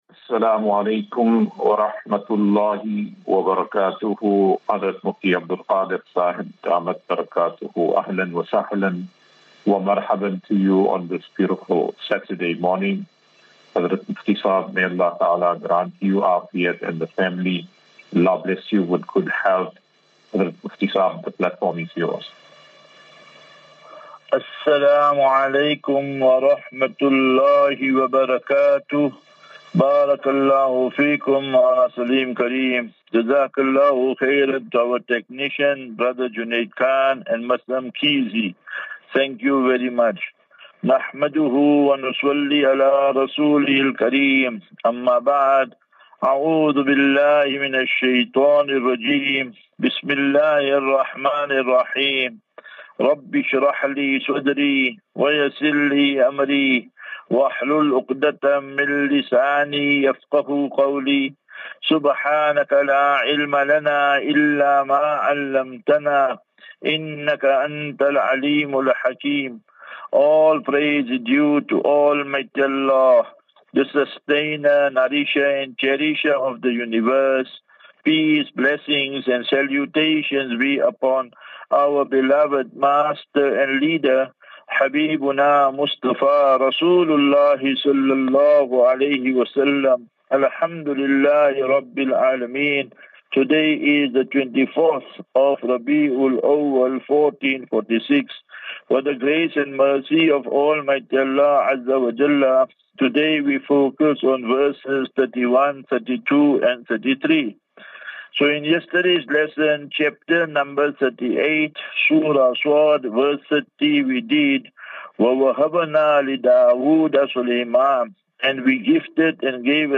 Assafinatu - Illal -Jannah. QnA